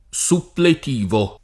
Supplet&vo] agg. — es.: elezioni suppletive; sessione suppletiva d’esami — non supplettivo, forma suggerita a qualcuno da una falsa analogia con collettivo e sim. (ma collettivo dal lat. collectivus der. di collectus [koll$ktuS], invece suppletivo dal lat. suppletivus der. di suppletus [